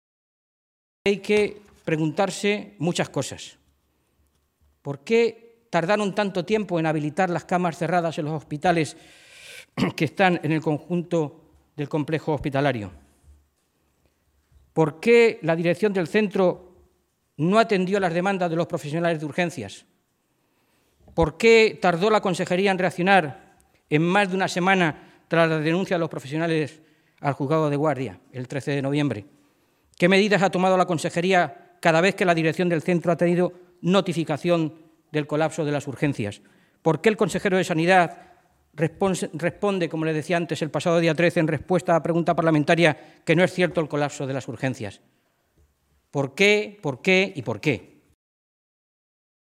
Fernando Mora, portavoz de Sanidad del Grupo Parlamentario Socialista
Cortes de audio de la rueda de prensa